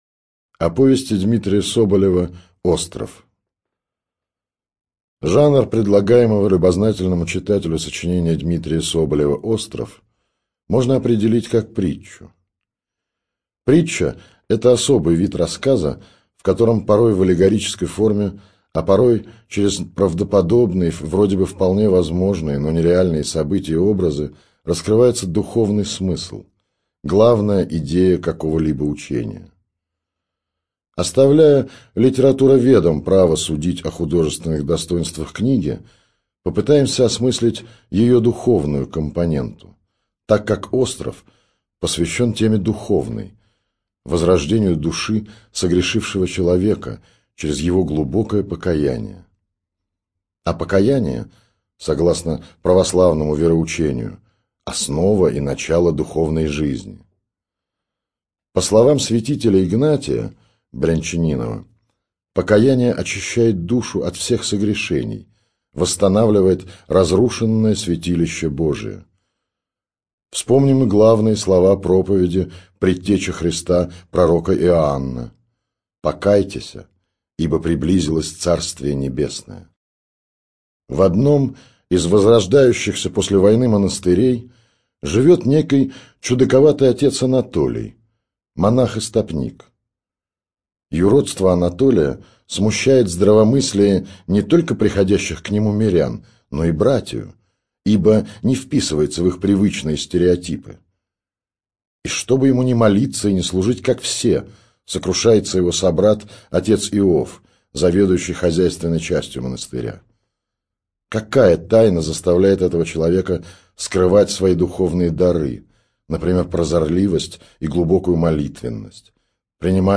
Студия звукозаписиАмфора